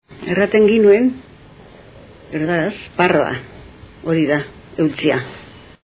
Dialectos
Salacenco